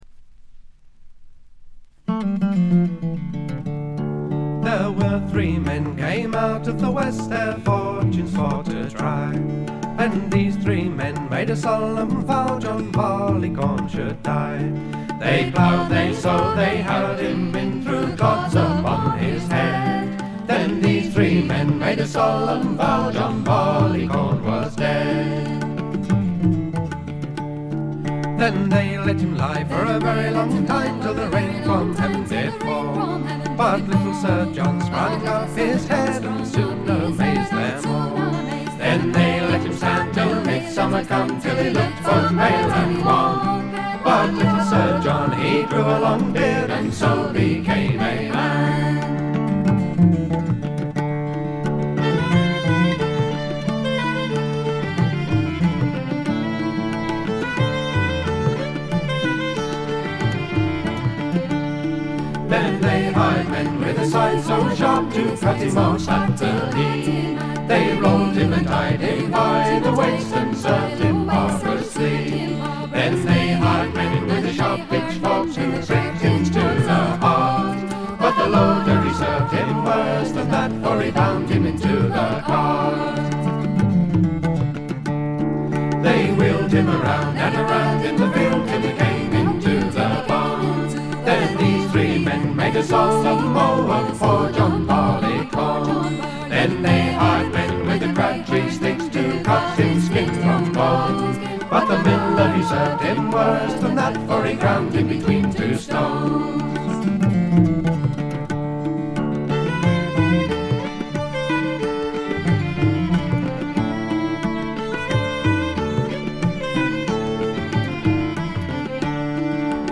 部分試聴ですがほとんどノイズ感無し。
また専任のタブラ奏者がいるのも驚きで、全編に鳴り響くタブラの音色が得も言われぬ独特の味わいを醸しだしています。
試聴曲は現品からの取り込み音源です。
vocals, flute, recorders, oboe, piccolo
fiddle, vocals
tabla, finger cymbals